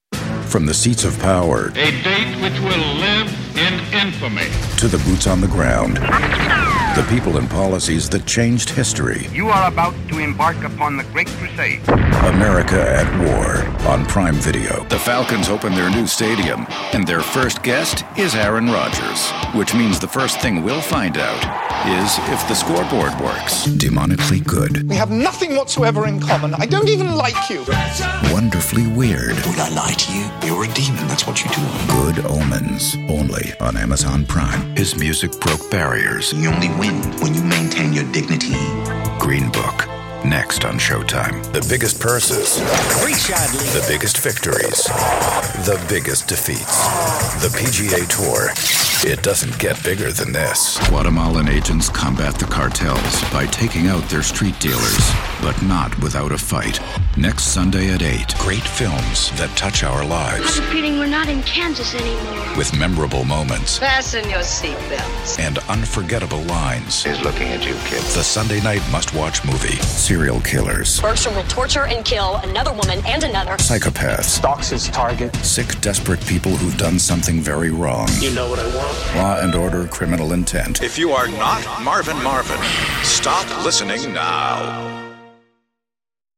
Never any Artificial Voices used, unlike other sites.
Male
English (North American)
Adult (30-50), Older Sound (50+)
Main Demo